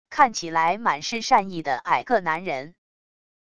看起来满是善意的矮个男人wav音频